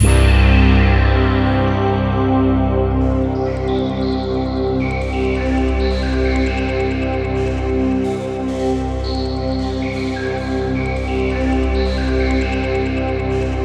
BRASSPADC2-R.wav